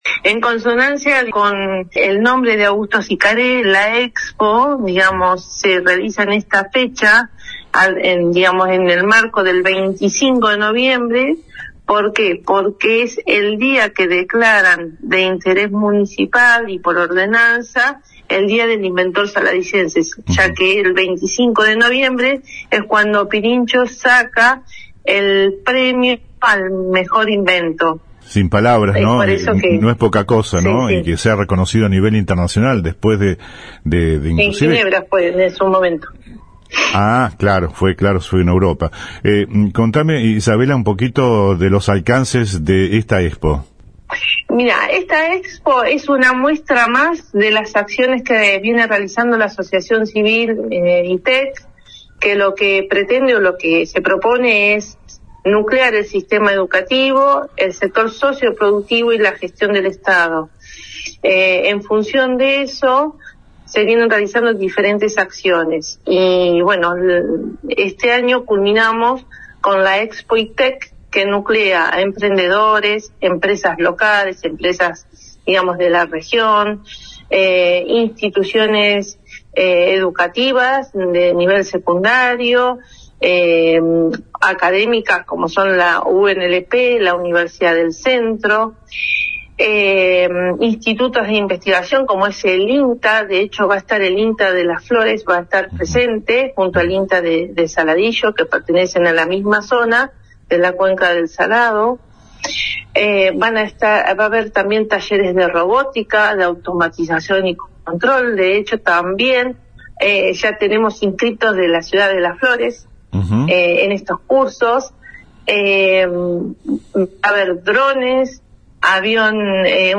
habló con los integrantes de la Ong organizadora